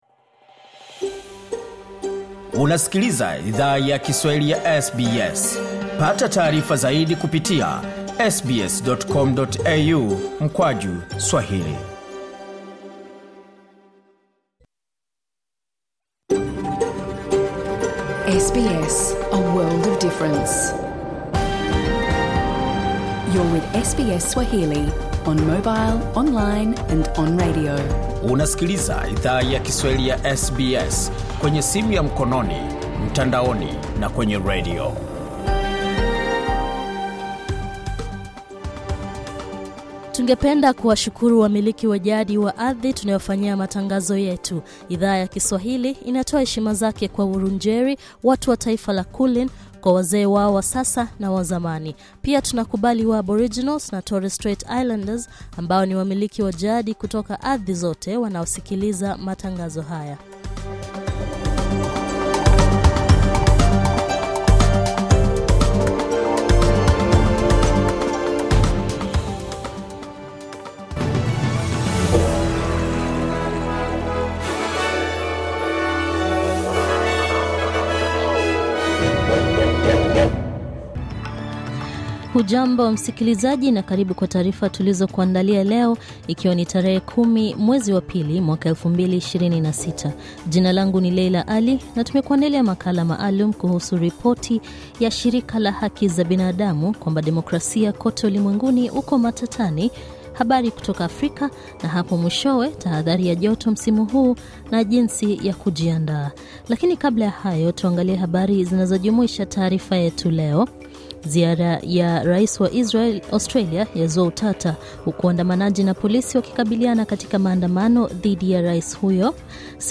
Taarifa ya habari:Ziara ya rais wa Israel Isaac Herzog, yazua utata